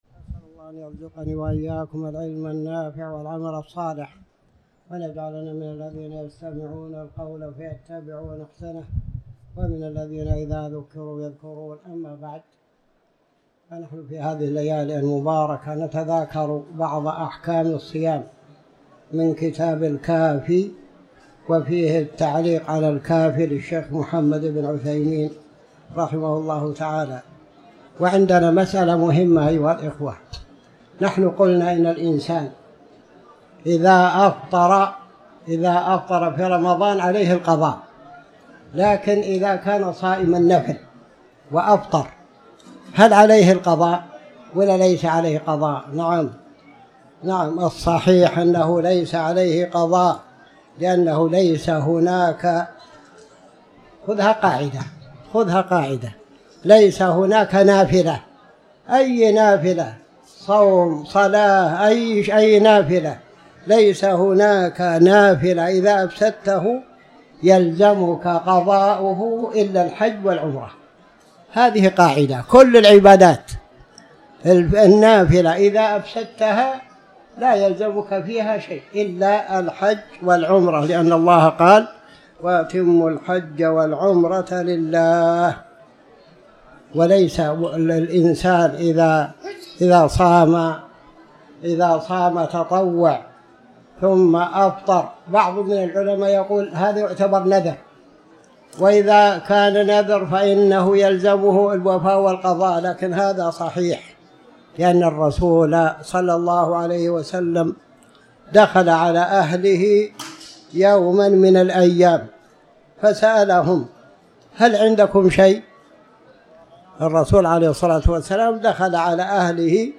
تاريخ النشر ٢ رمضان ١٤٤٠ هـ المكان: المسجد الحرام الشيخ